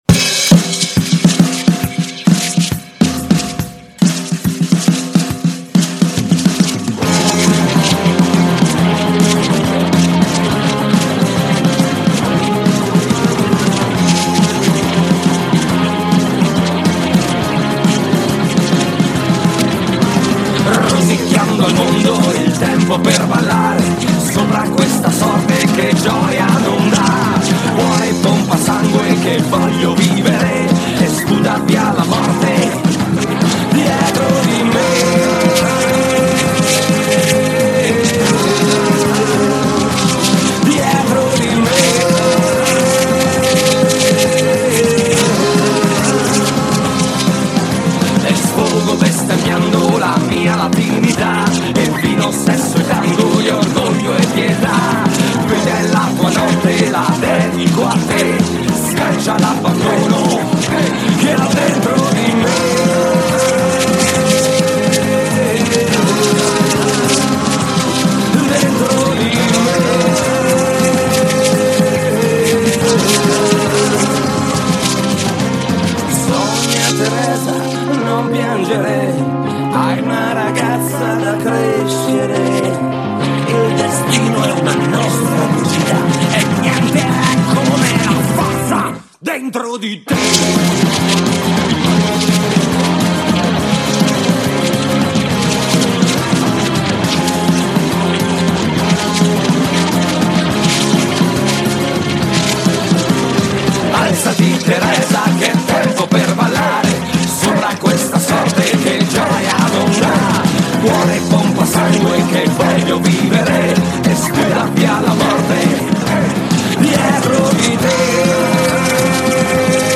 Rede & Aufruf Feministische Aktion 8. März: 7:14
Grußworte Feminism Unstoppable München: 0:53